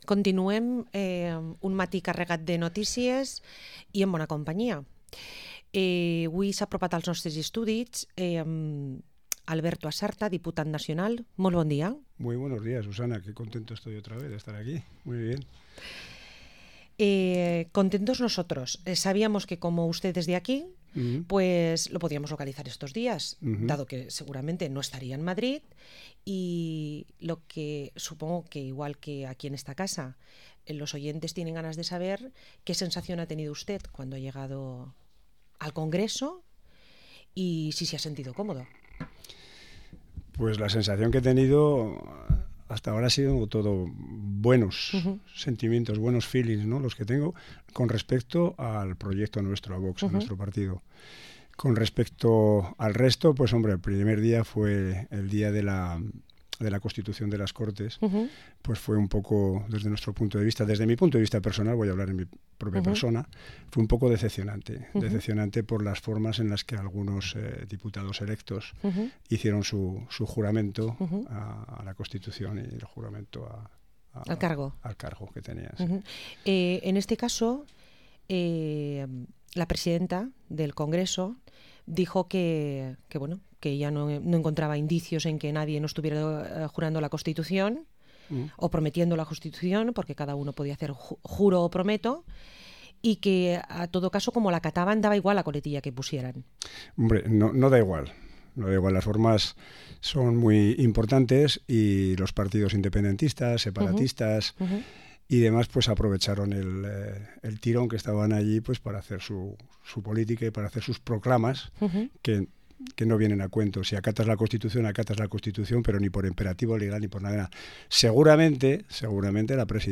Entrevista al Diputado Nacional de VOX, Alberto Asarta